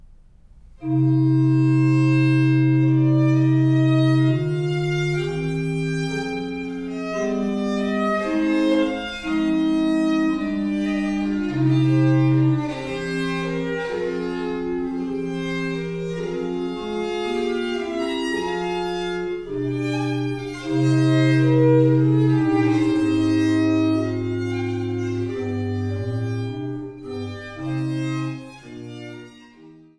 Barockgeige
Orgel